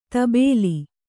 ♪ tabēli